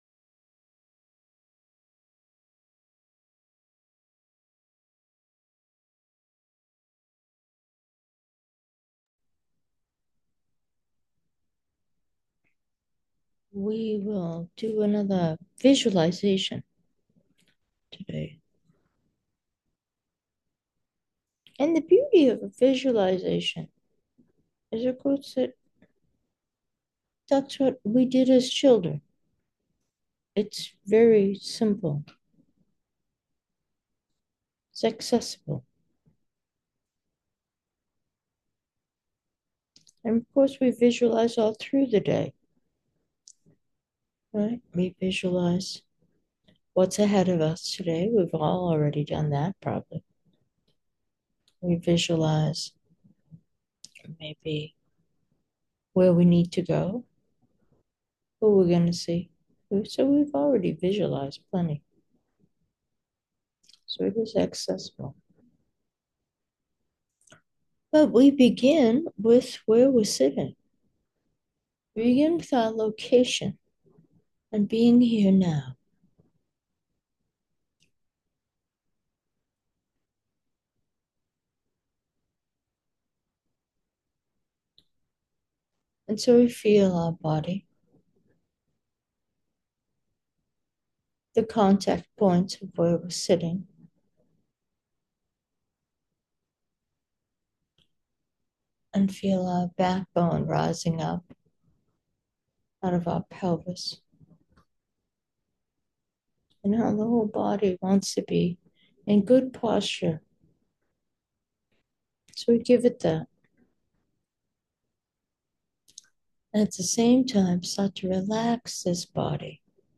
Meditation: lotus light being